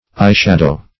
\eye"shad`ow\([imac]"sh[a^]d`[=o]), n.